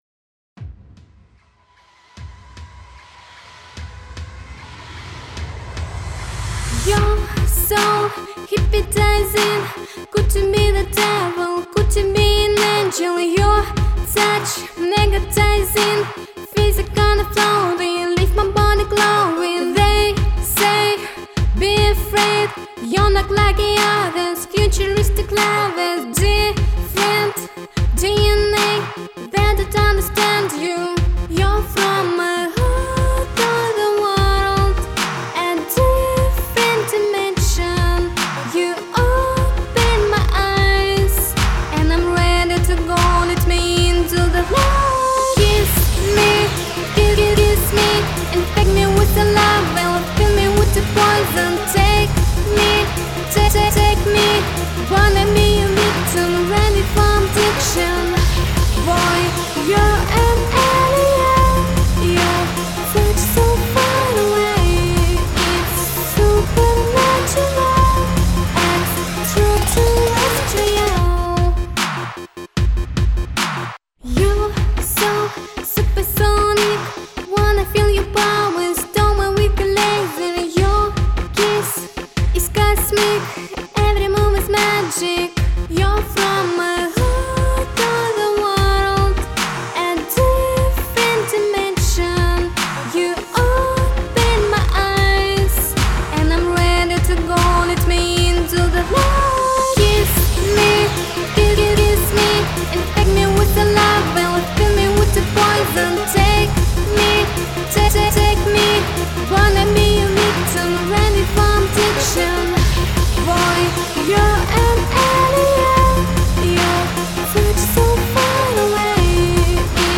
любительский вокал, свежий и современный